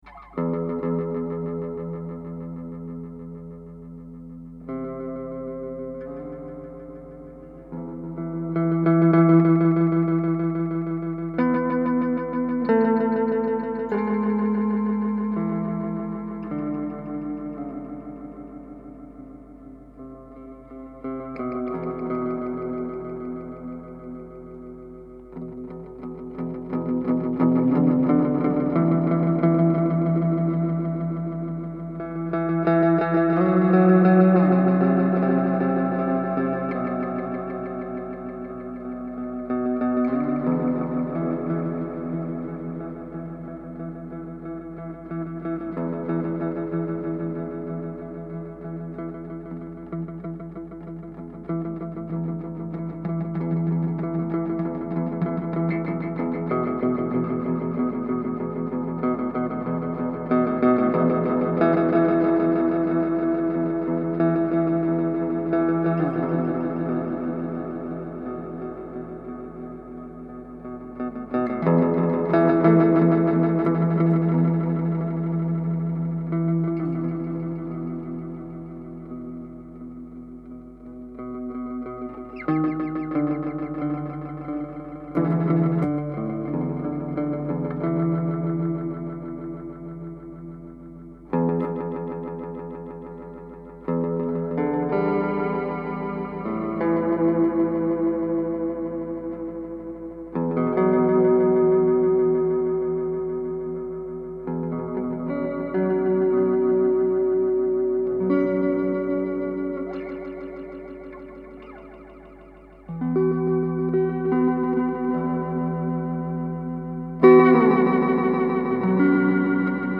I found an old electric guitar with 3 strings. Without a guitar cord, I attached a transducer pickup (made for acoustics) to the body, and connected it to an effect insert with the echo pedal. first of 3.